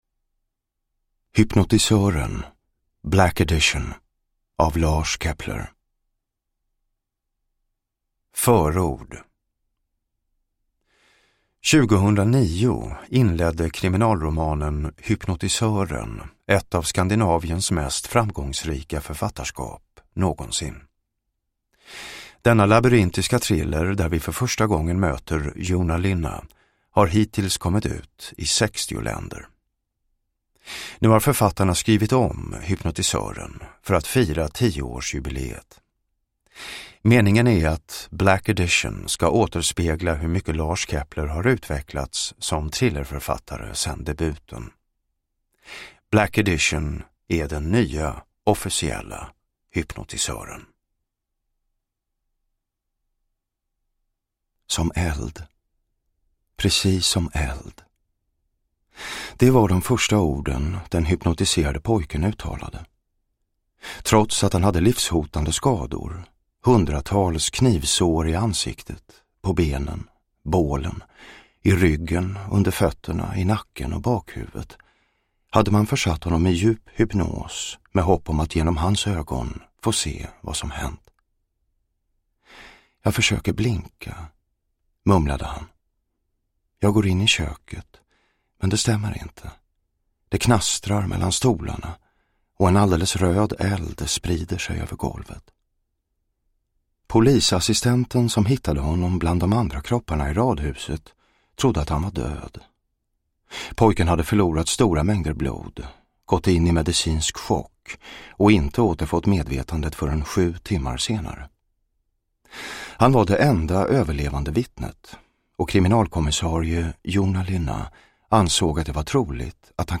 Hypnotisören - Black edition / Ljudbok
Jonas Malmsjö läser Black Edition, den nya officiella Hypnotisören.